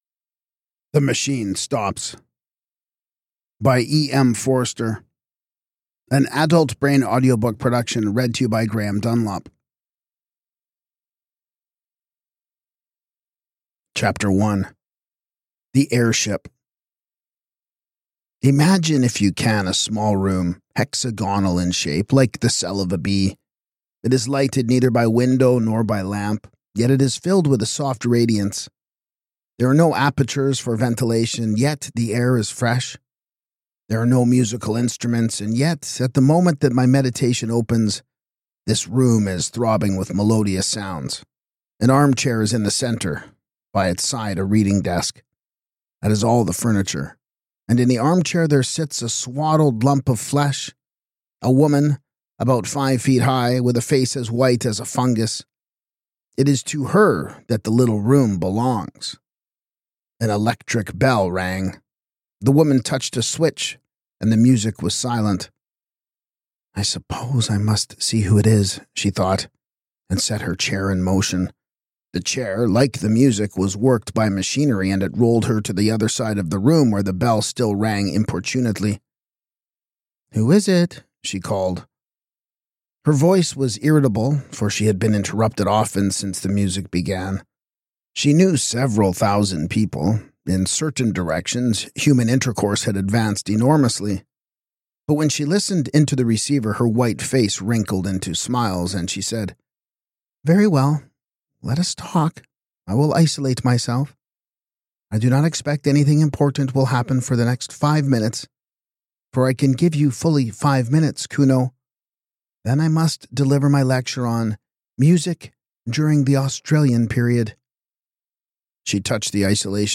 Podcast (audiobooks): Play in new window | Download